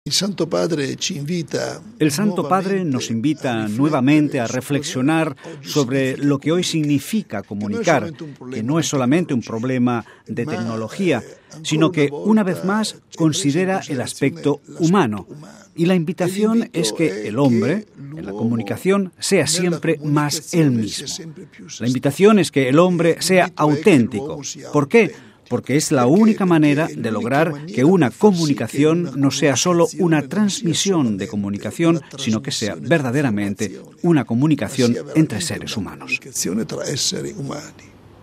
Estas fueron las palabras del presidente del Pontificio Consejo para las Comunicaciones Sociales, Mons. Claudio Maria Celli, que ha presentado hoy este documento pontificio: